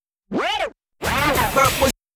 As a final tweak add 33% rectifier distortion …